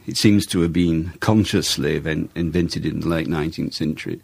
So, again we are left with just a schwa: /ə/.